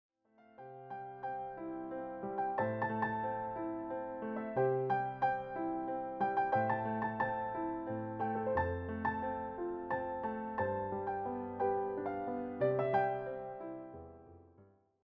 all reimagined as solo piano pieces.
just the piano, no vocals, no band.